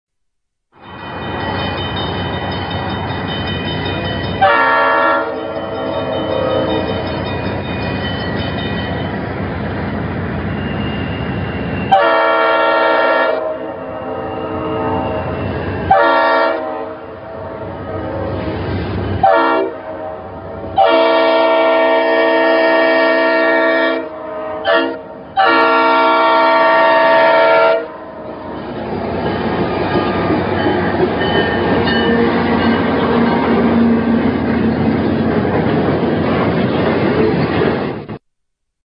AudioNS 1652 has a classic Leslie S5T horn.